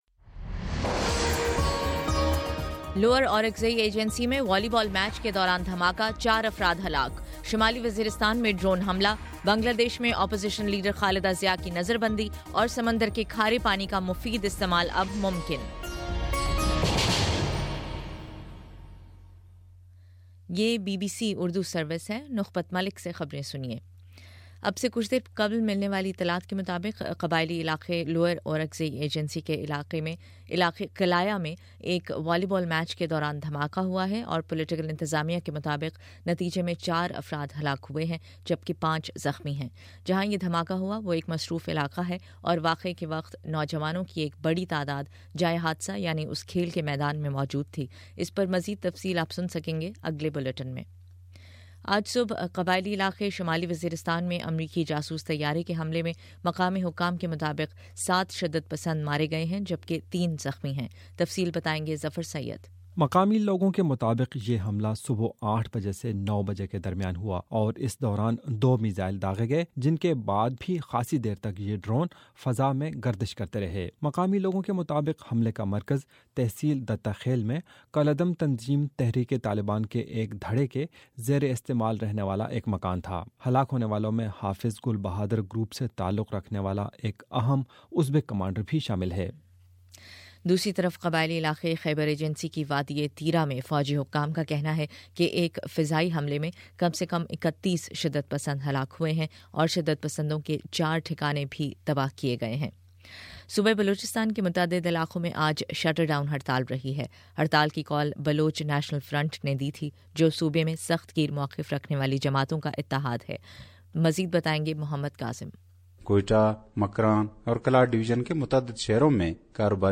جنوری04 : شام چھ بجے کا نیوز بُلیٹن